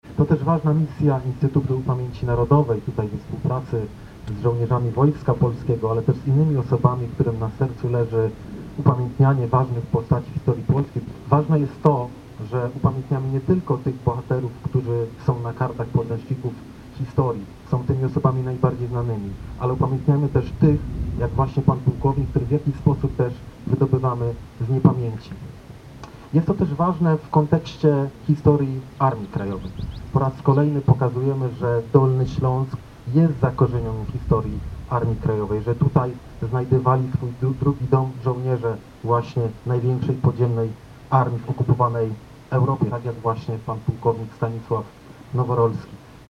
Jak zaznaczono podczas uroczystości, szczególne znaczenie ma przywracanie pamięci o osobach mniej znanych, które nie zawsze trafiły do podręczników historii.